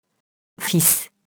fils [fis]